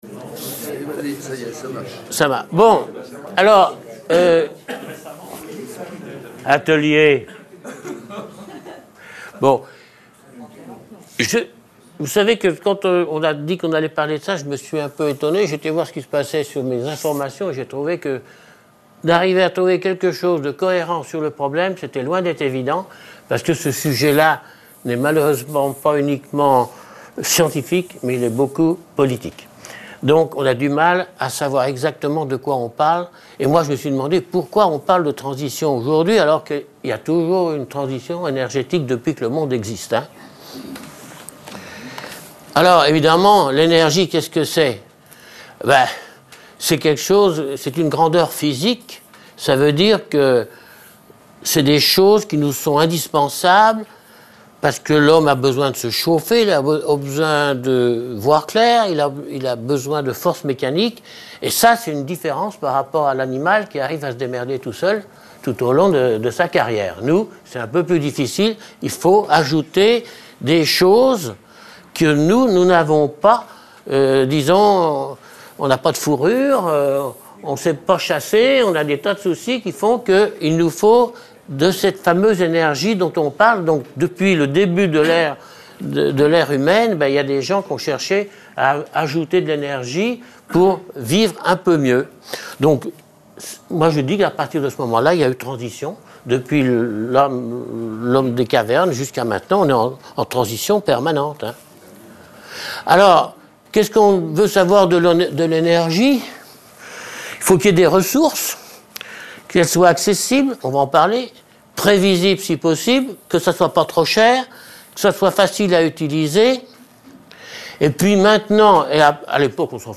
Conférence
sur la transition energétique - Novotel Montchanin